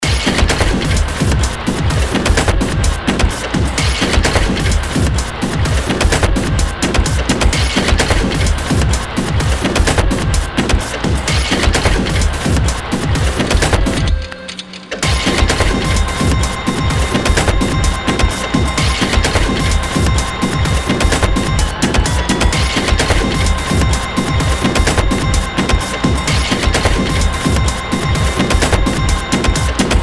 Good example of some of the unorthodox percussion and sound I'll have quite a bit of in my upcoming release: